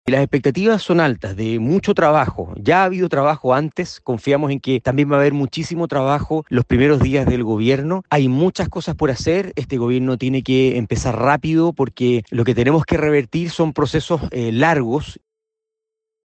Por último, el diputado republicano Stephan Schubert añadió que, para su sector, las expectativas respecto del próximo gobierno son bastante altas.